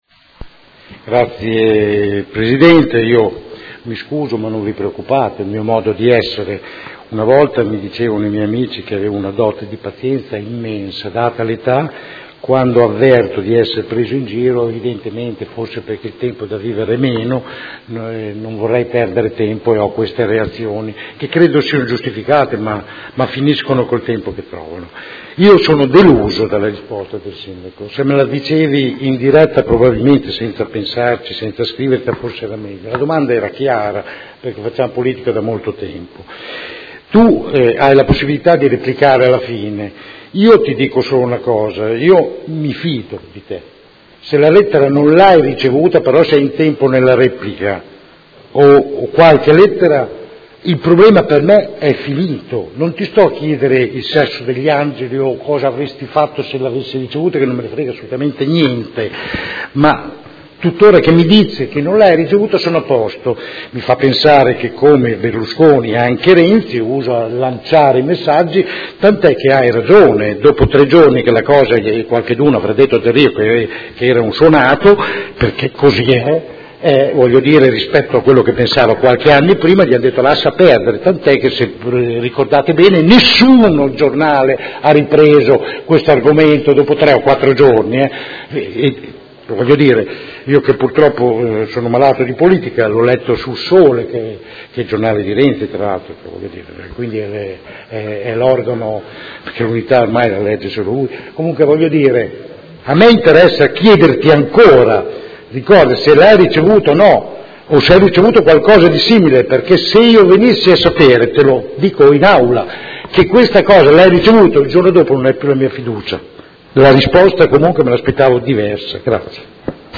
Seduta del 22/09/2016 Interrogazione del Consigliere Rocco (FAS-SI) e del Consigliere Campana (PerMeModena) avente per oggetto: Lettera del Ministro delle Infrastrutture Del Rio. Replica